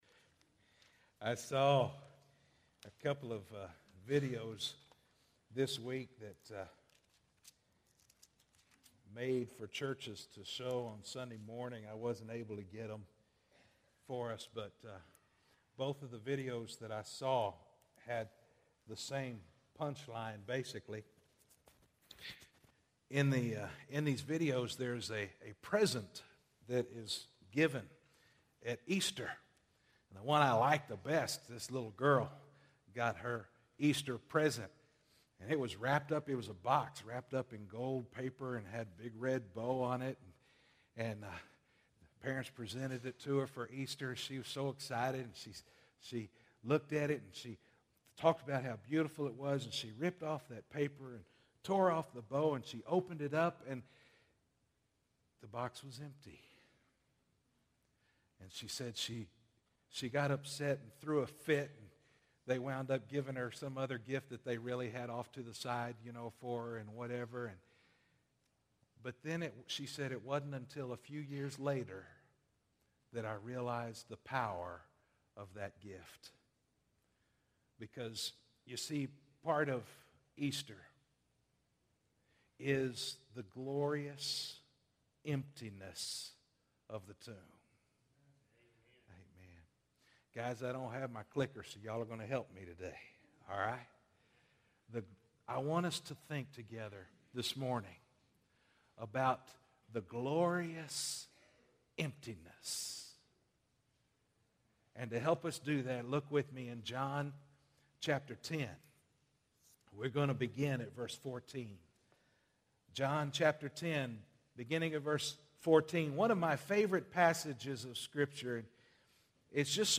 Easter Message